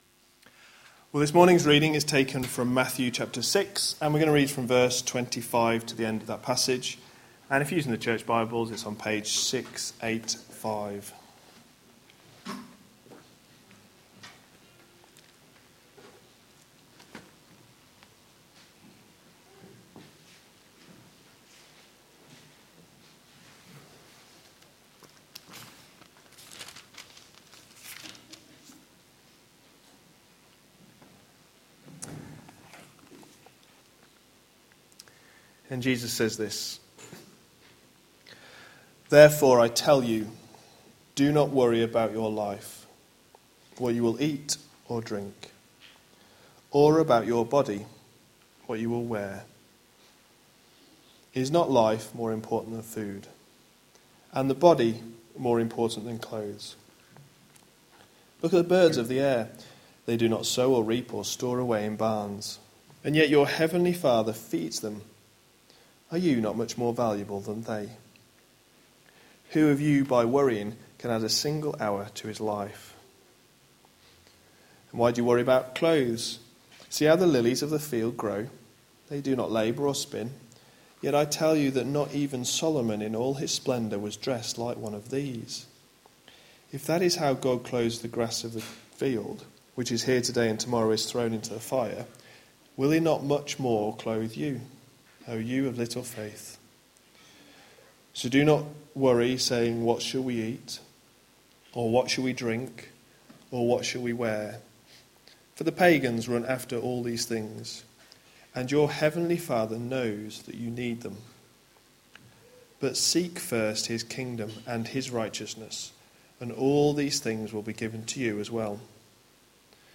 A sermon preached on 8th May, 2016, as part of our Anxiety in the now and not yet series.